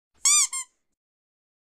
Tiếng Vịt đồ chơi Meme
Description: Hiệu ứng âm thanh tiếng bóp vịt đồ chơi đã lan truyền trên mạng xã hội, thường được sử dụng trong các meme vui nhộn và video đã chỉnh sửa.
suara-bebek-mainan-meme-id-www_tiengdong_com.mp3